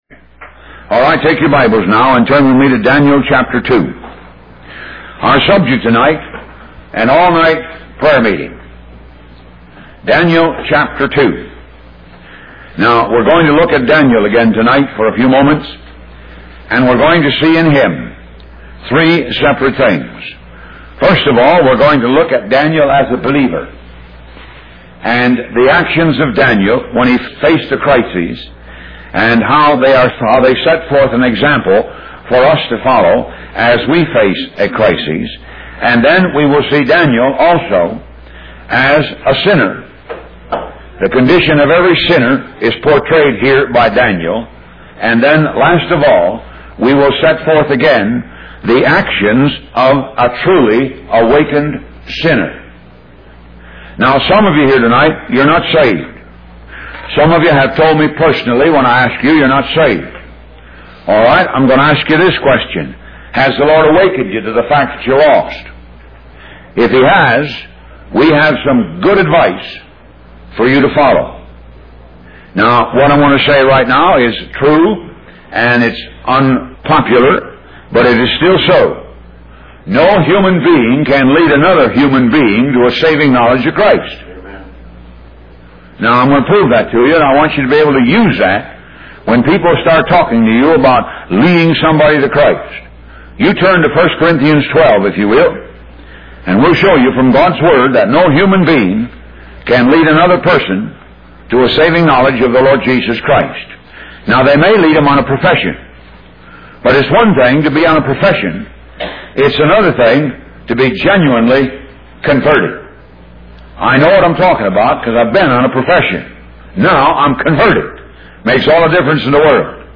Talk Show Episode, Audio Podcast, Moga - Mercies Of God Association and An All Night Prayer Meeting on , show guests , about An All Night Prayer Meeting, categorized as Health & Lifestyle,History,Love & Relationships,Philosophy,Psychology,Christianity,Inspirational,Motivational,Society and Culture